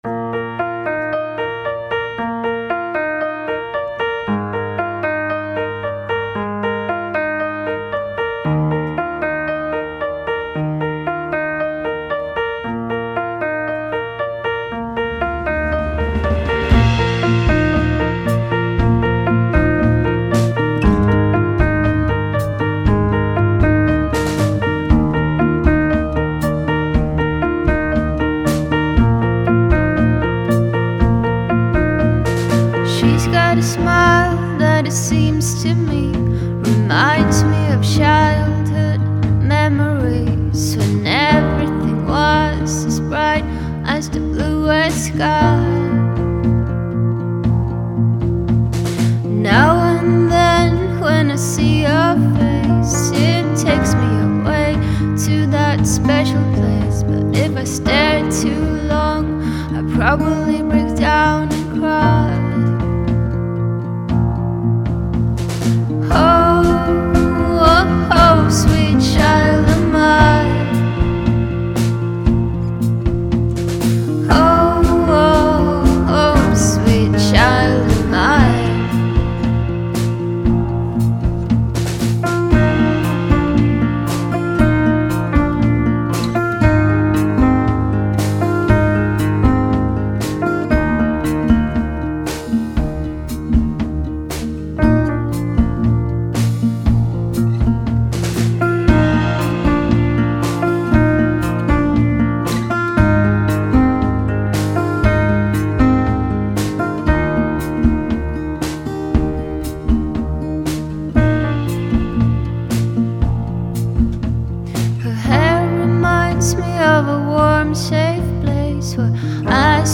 which marries many different sounds.